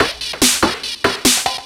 DS 144-BPM A5.wav